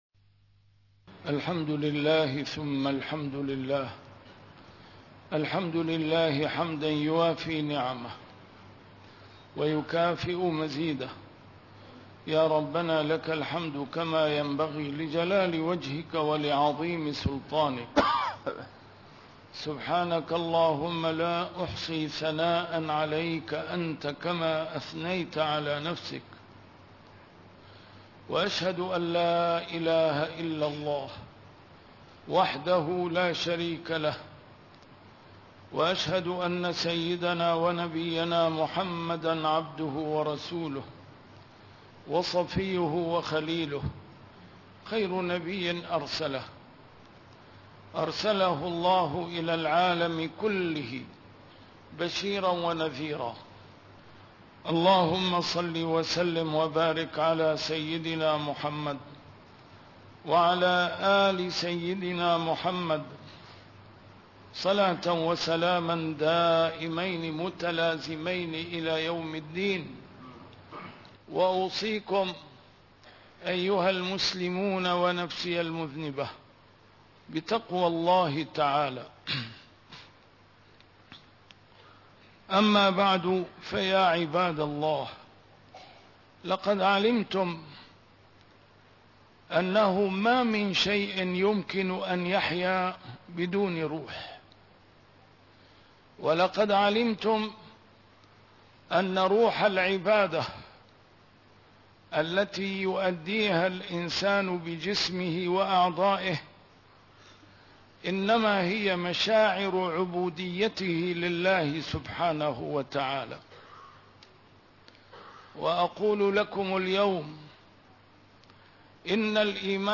A MARTYR SCHOLAR: IMAM MUHAMMAD SAEED RAMADAN AL-BOUTI - الخطب - الدنيا يومٌ له غدٌ طويل